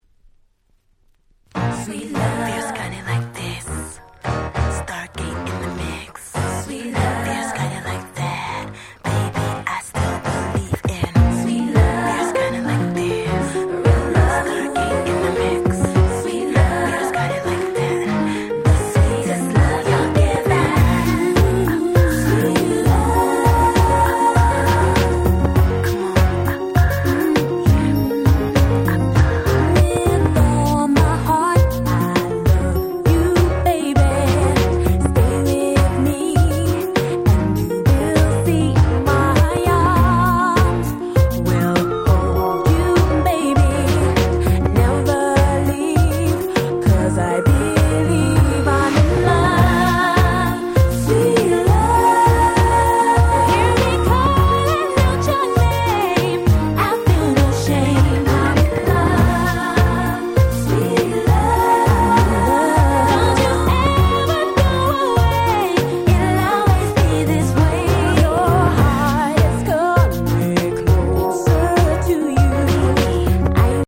99' Nice EU R&B !!